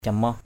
/ca-mɔh/